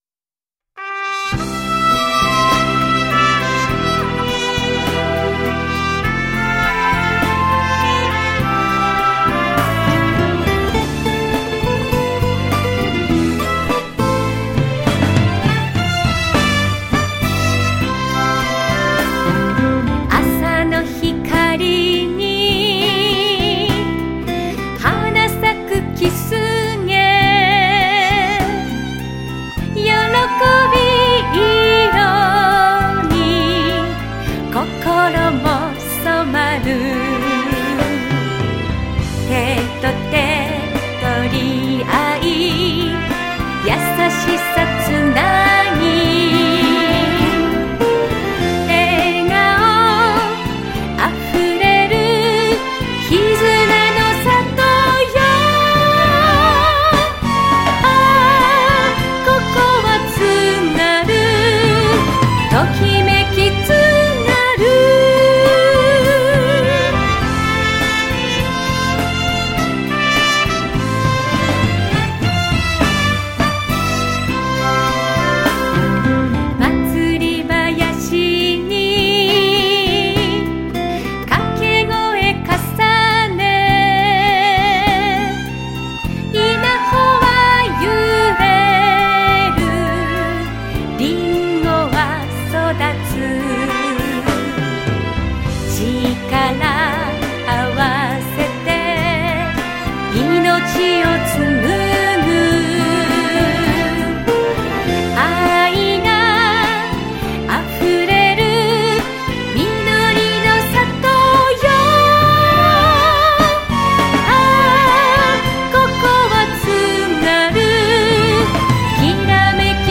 ソロ女性歌唱